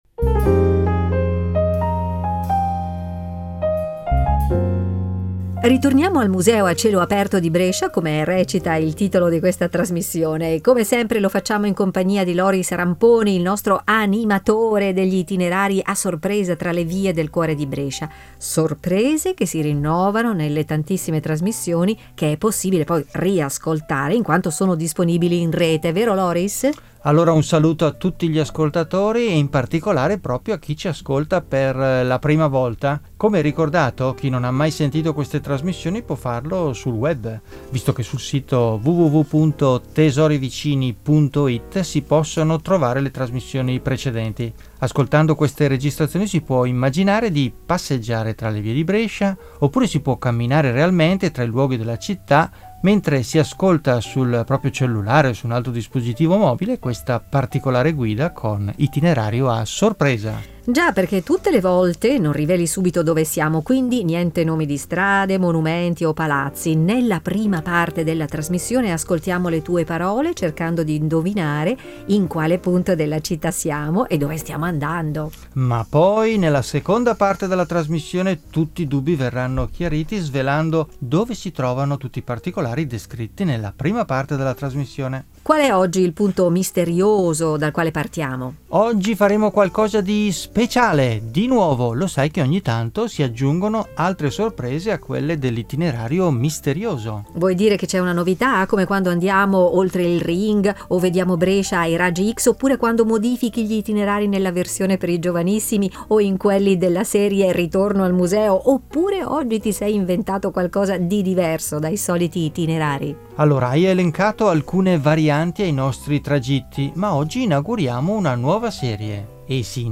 audio-guida e itinerari per passeggiare tra le vie di Brescia